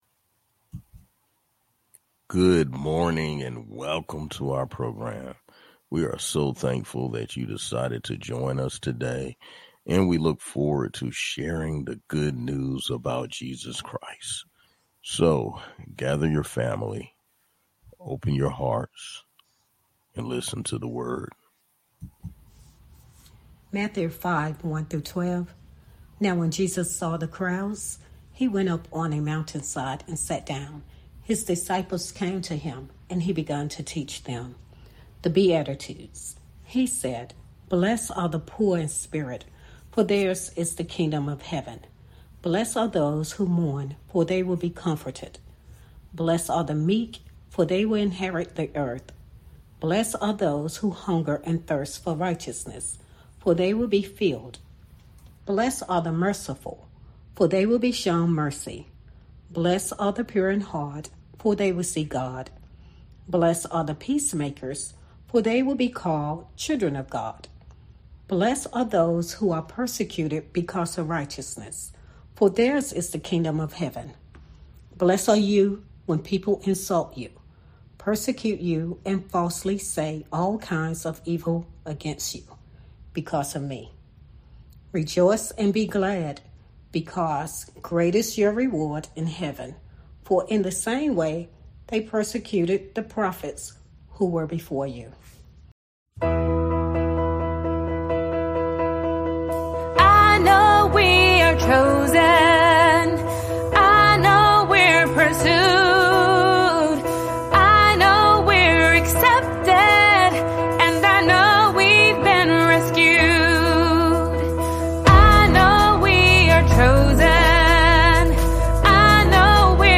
Message: Are You Blessed?